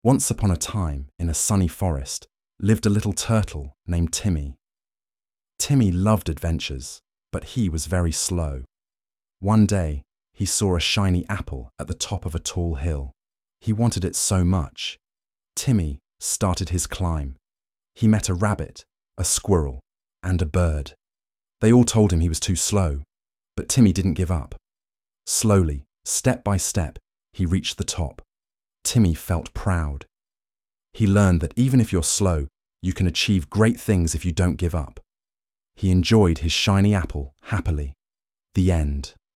Seguramente a funcionalidade más prezada é a conversión de texto a voz.
conto_ingles.mp3